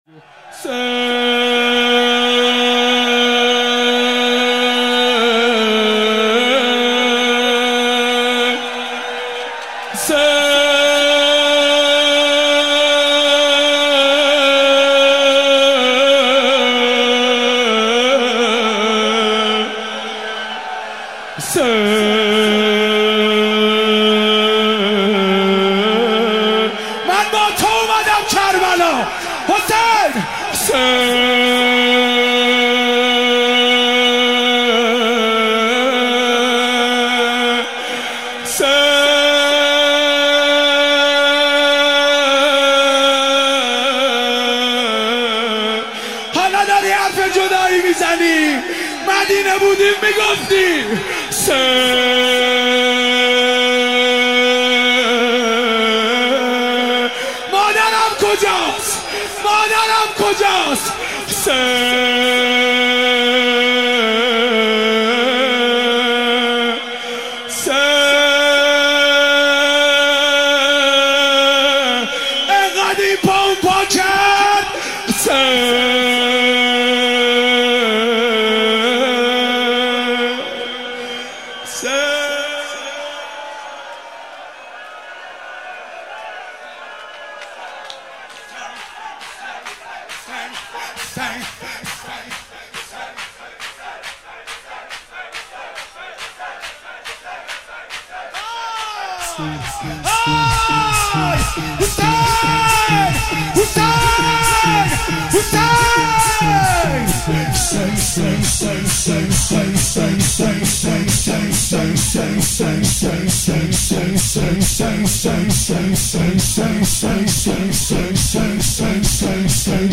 14-Rozeh & Zekr.mp3